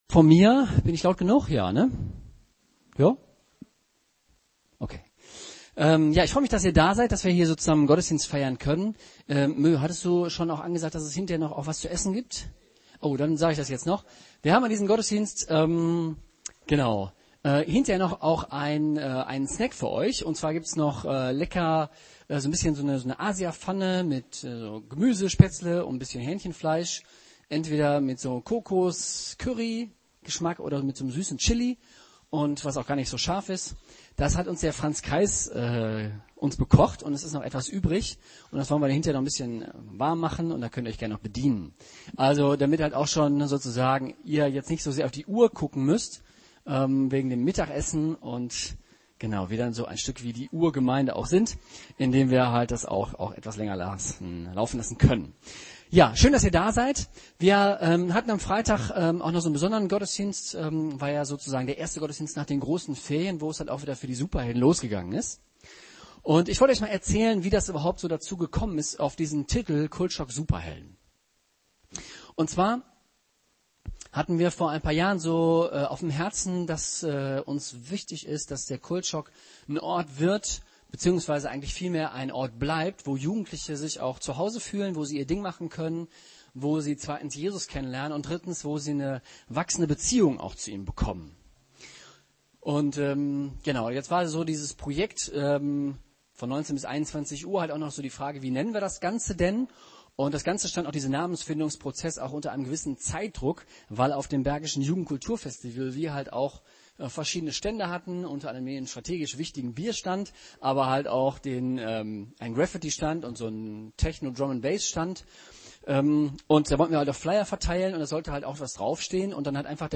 Predigten der Jesus Freaks Remscheid